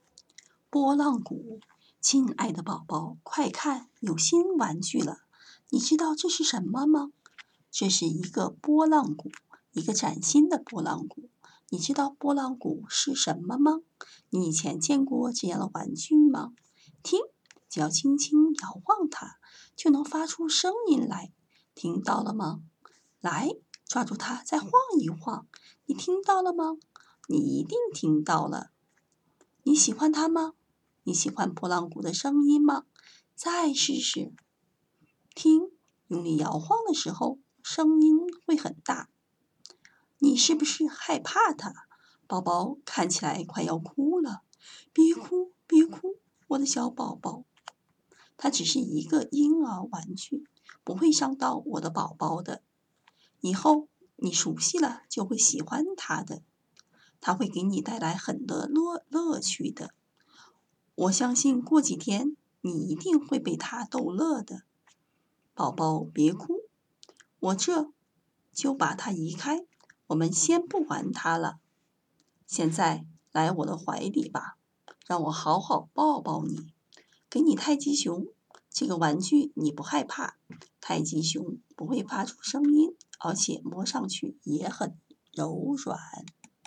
à la voix douce, bien posée, et à la prononciation impeccable et adaptée.